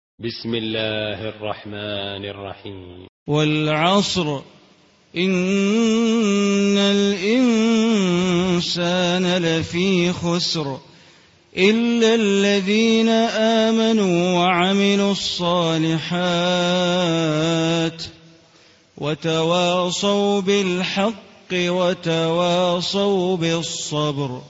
Surah Asr Recitation by Sheikh Bandar Baleela
Surah Asr, listen online mp3 tilawat / recitation in Arabic in the beautiful voice of Imam e Kaaba Sheikh Bandar Baleela.